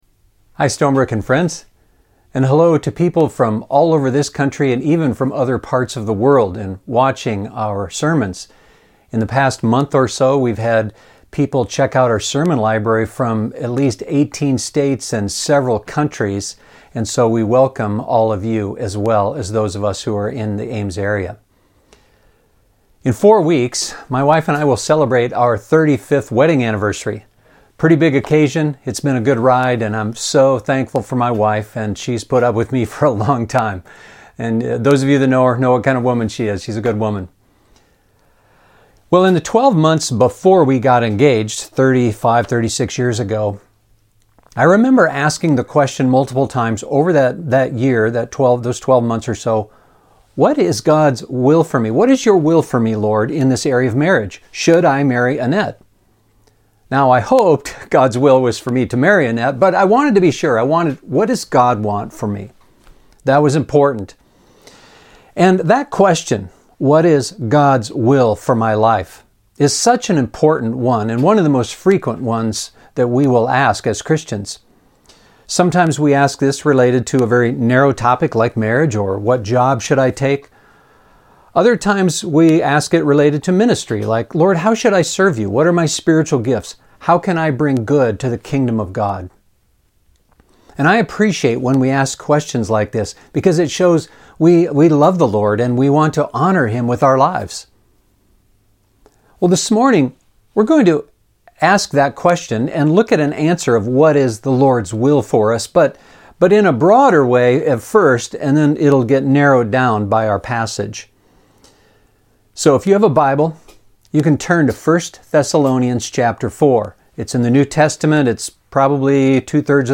We are in the middle of a sermon series going through a letter written by the Apostle Paul.